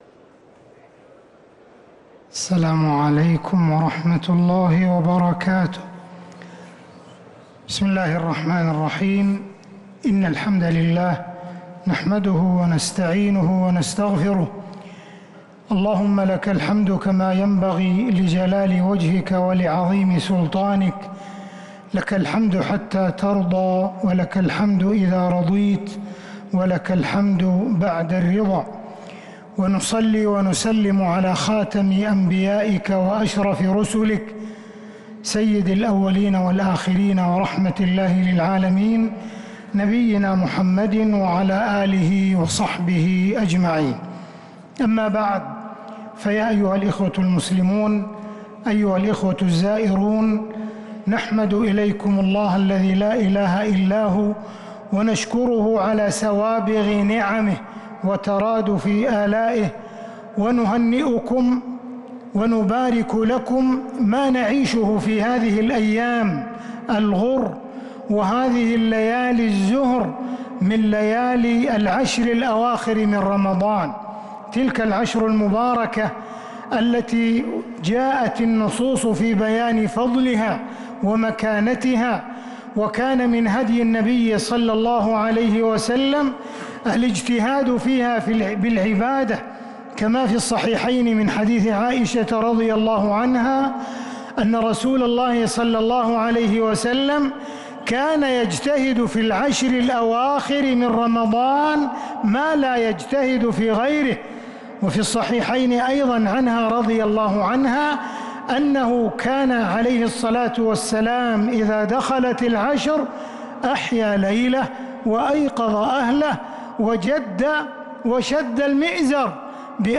كلمة الشيخ عبدالرحمن السديس بالمسجد النبوي بعد صلاة العشاء 22 رمضان 1446هـ > كلمات أئمة الحرم النبوي 🕌 > المزيد - تلاوات الحرمين